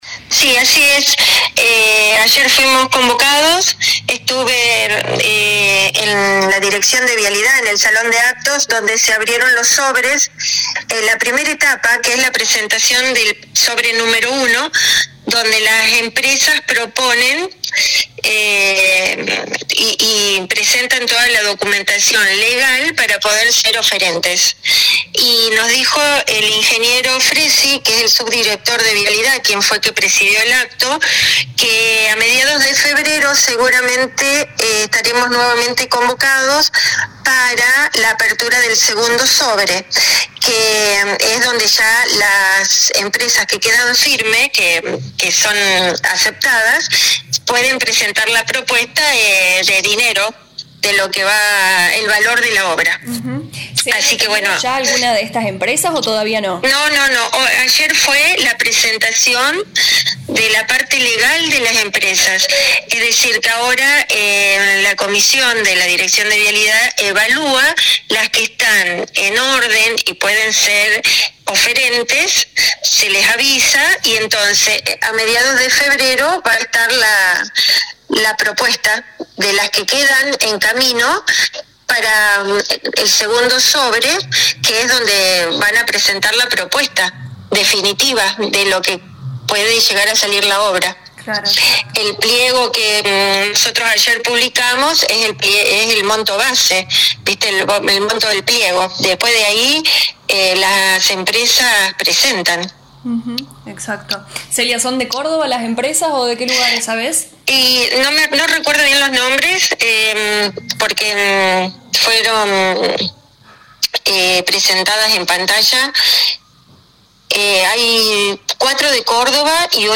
En diálogo con LA RADIO 102.9 FM la intendente de Seeber Celia Giorgis destacó que participó en Córdoba de la primera apertura de sobres del llamado a licitación del proyecto “Rotonda en Intersección de Rutas 1 y 17”.